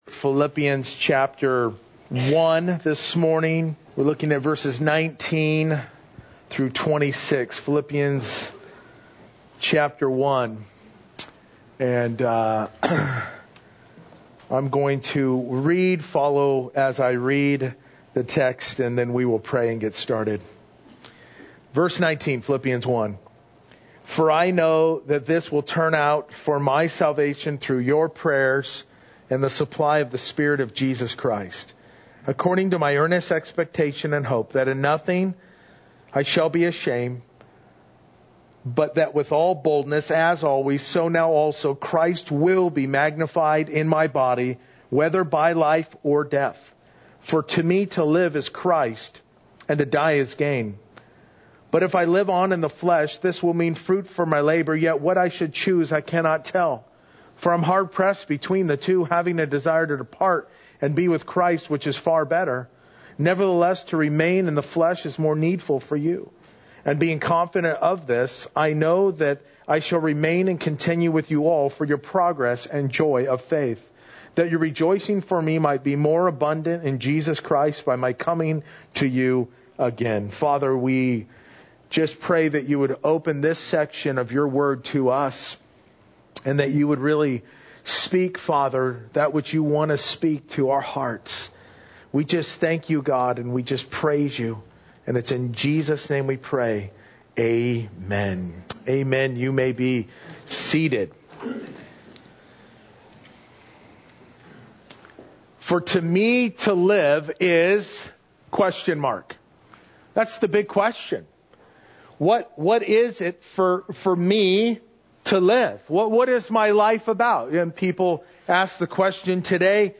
Verse by Verse-In Depth « Scripture Fulfilled at Calvary